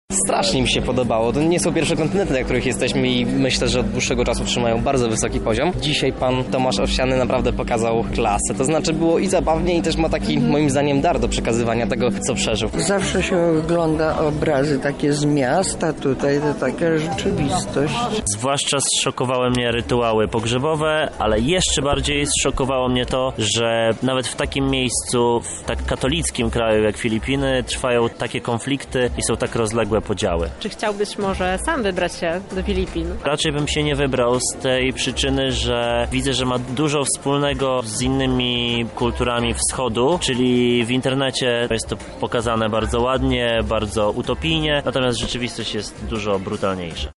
W poniedziałek, 9 kwietnia w Centrum Spotkania Kultur mieliśmy okazję poznać dość nieznane zakątki Azji.
Kontynenty – sonda